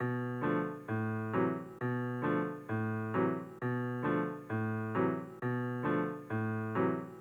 piano_133.wav